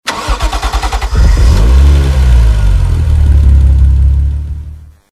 Home gmod sound vehicles tdmcars supra
enginestart.mp3